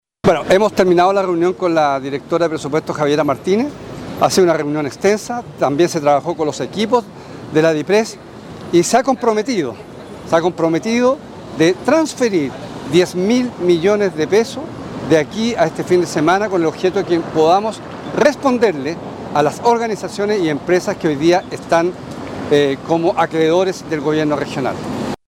Alejandro Santana detalla los pormenores con la Dirección de Presupuestos de la nación en Santiago.